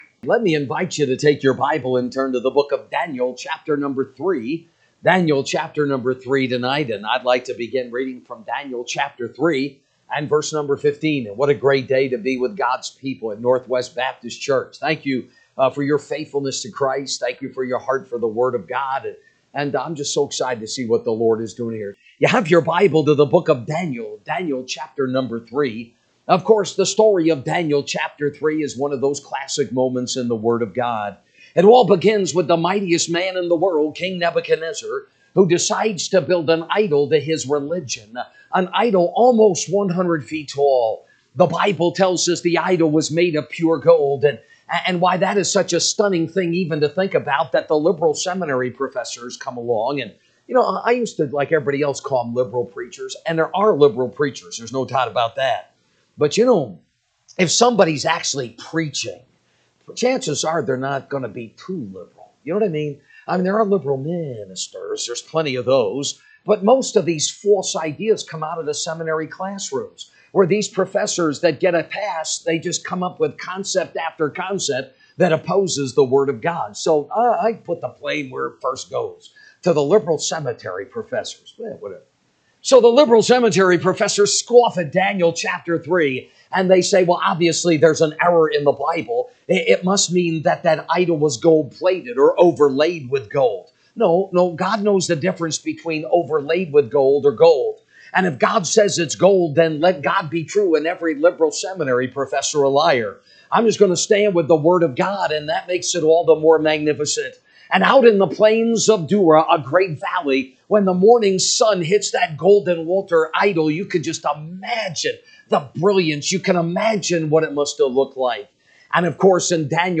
March 16, 2025 pm Service Daniel 3:15-19 (KJB) 15 Now if ye be ready that at what time ye hear the sound of the cornet, flute, harp, sackbut, psaltery, and dulcimer, and all kinds of musick, y…